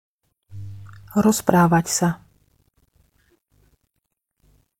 pronunciation_sk_rozpravat_sa.mp3